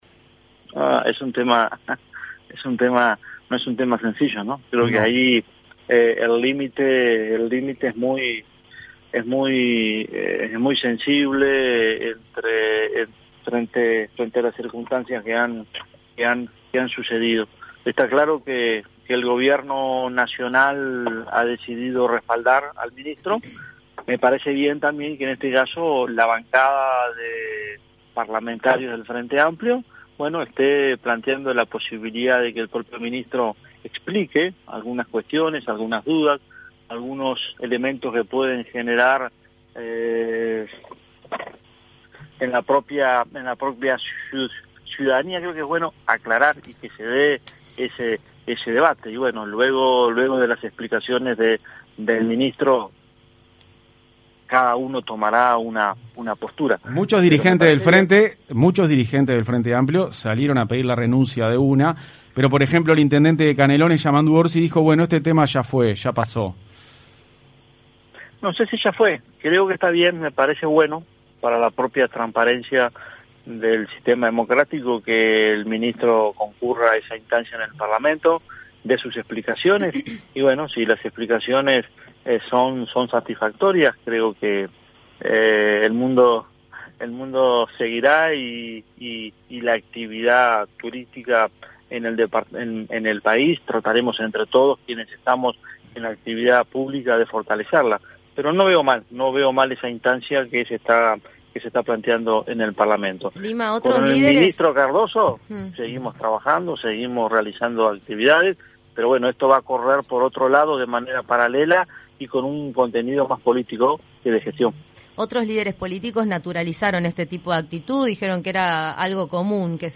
El intendente de Salto, Andrés Lima, opinó en entrevista con Punto de Encuentro que el tipo de actitudes que tuvo el ministro de Turismo, Germán Cardoso, para con el coordinador de policía imputado le parecen «mal».